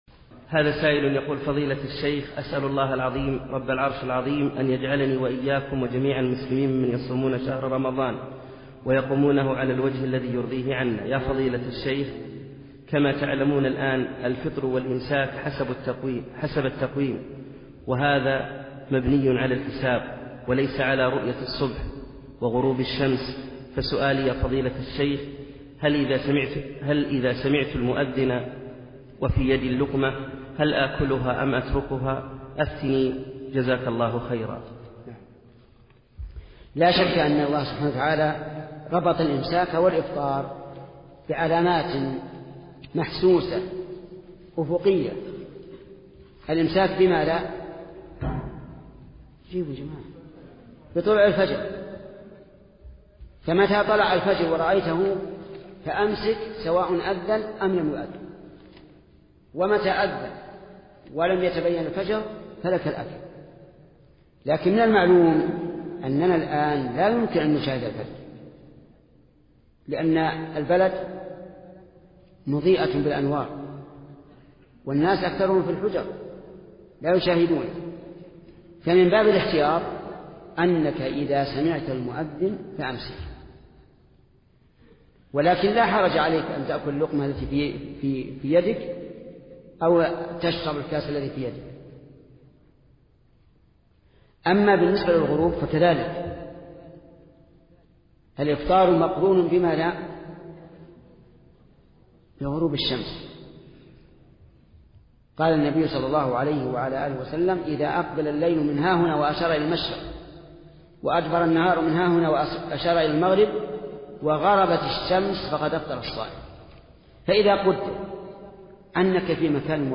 الفتاوى  |  هل له أن يأكل اللقمة التي بيده إذا سمع أذان الفجر | محمد بن صالح العثيمين